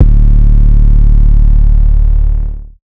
TS 808_3.wav